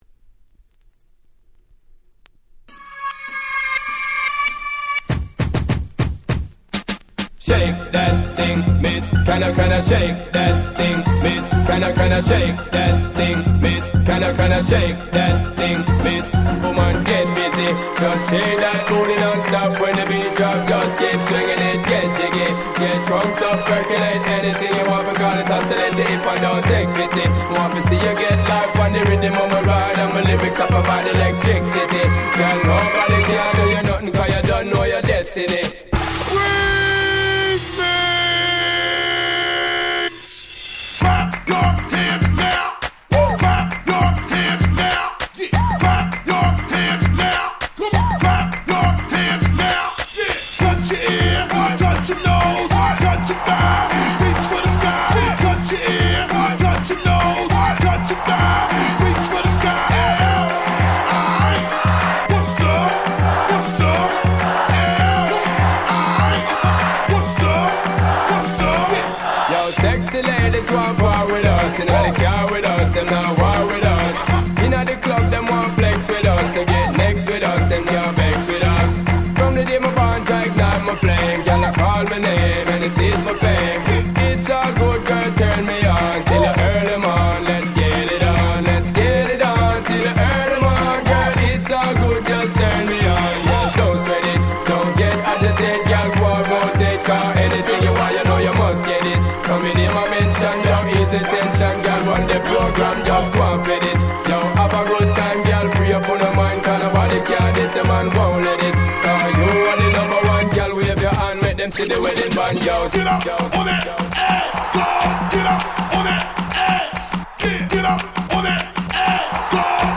06' Smash Hit Dancehall Reggae !!